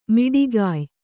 ♪ miḍigāy